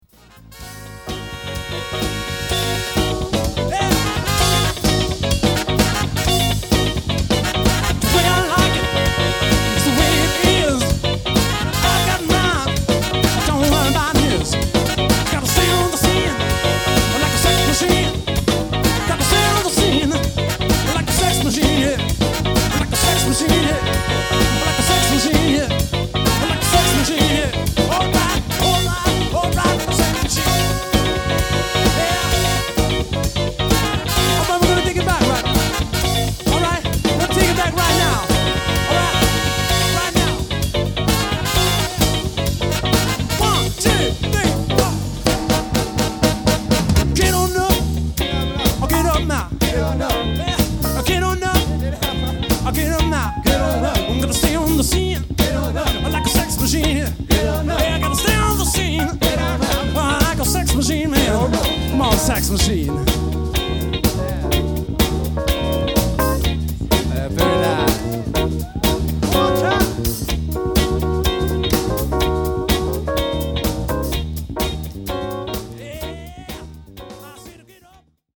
Funky Band
Sax, Flute
Trumpet
Trombone
Tight and funky hält sie den Groove und die Party am Laufen.
Bass, Vocals
Guitar, Vocals
Keyboards
Drums